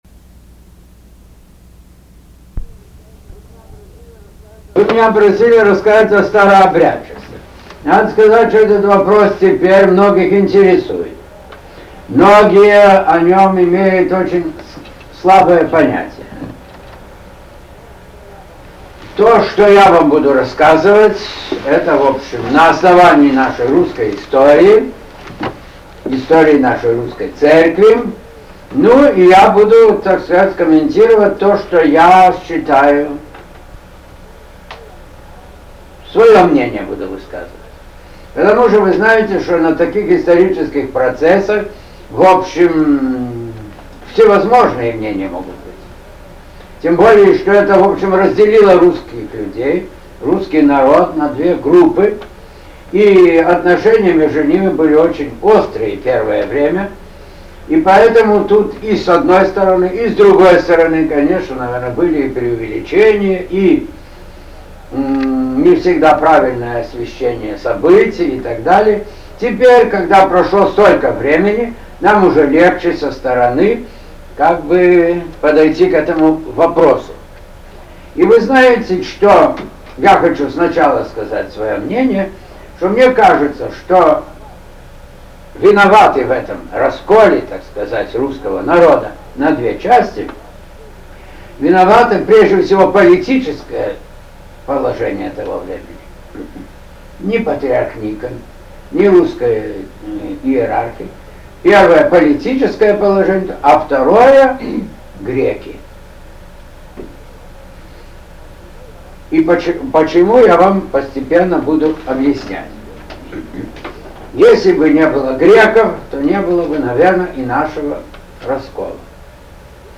Беседа Архиепископа Женевского Антония (Бартошевича) о старообрядчестве…
беседа-Архиеп-Антония-о-старообрядчестве-.mp3